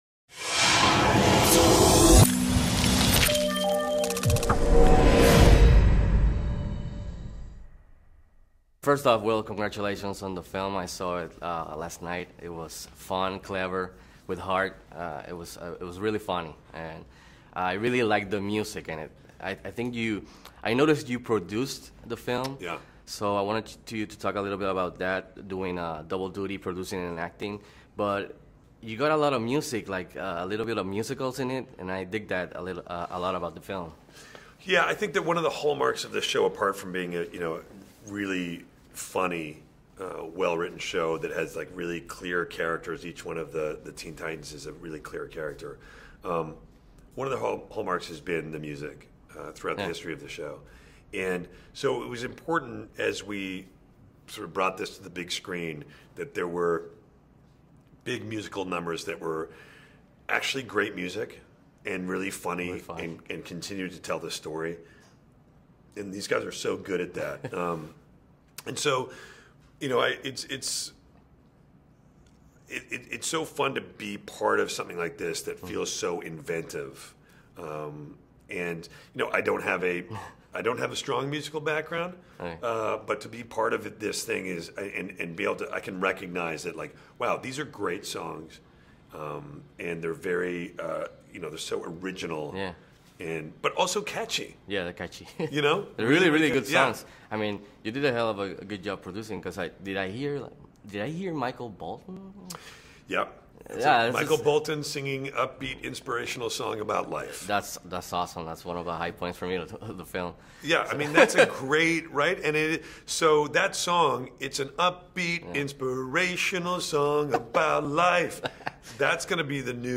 Entrevista: Will Arnett – Teen Titans Go! To The Movies (audio)
Interview-Will-Arnett-Teen-Titans-Go-To-The-Movies.mp3